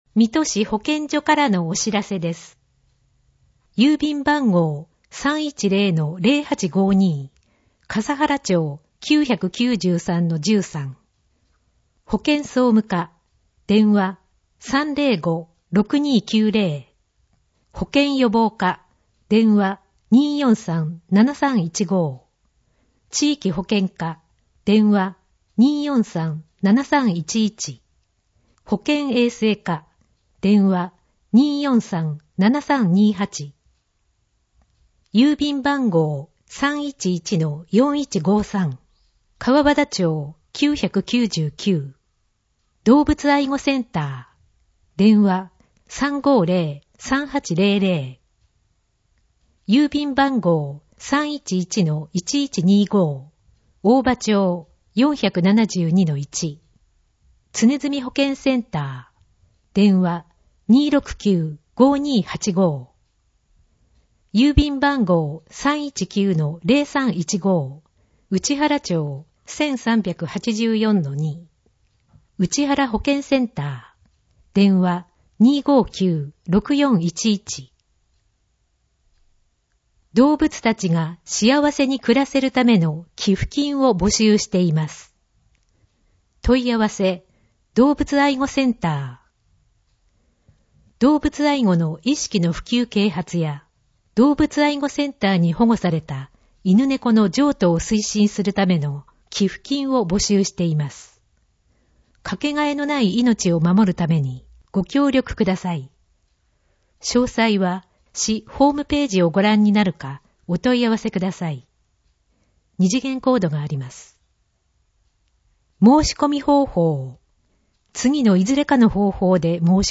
声の広報みと令和4年11月15日号